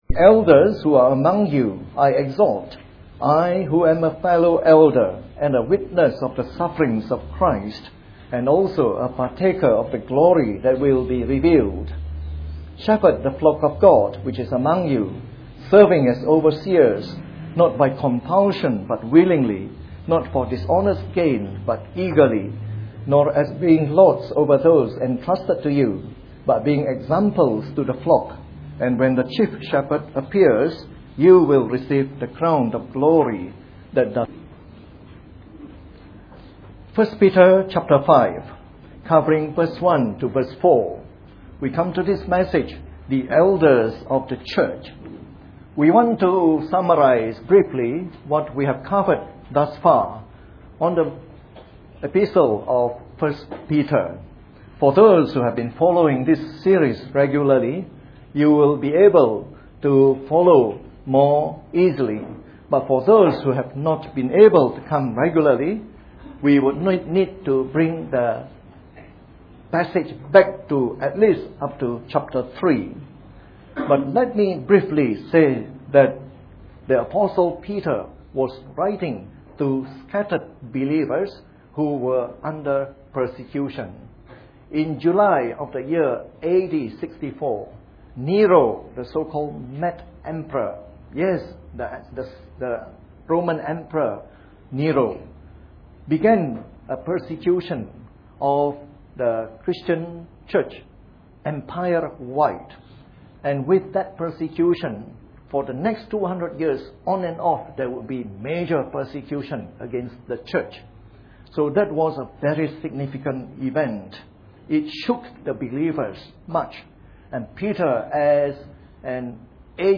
Part of our series on “The Epistles of Peter” delivered in the Evening Service.